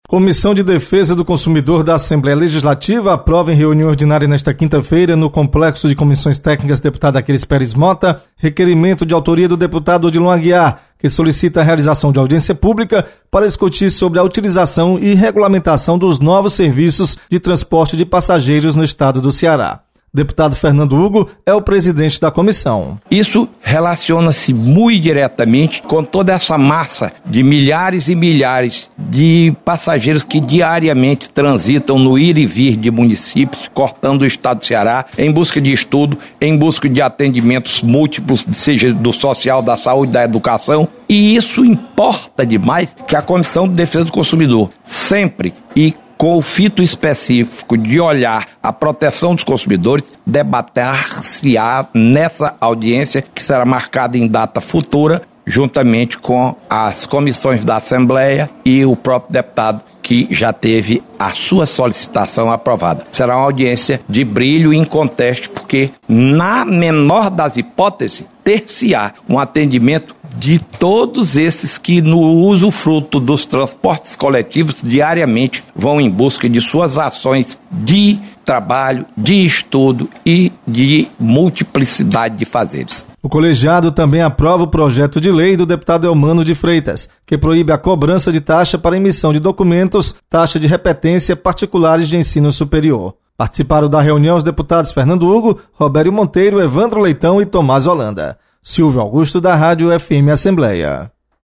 Audiência vai discutir regulamentação de transporte no Ceará. Repórter